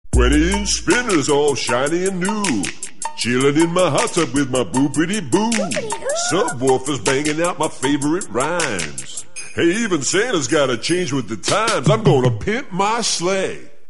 Rap - RnB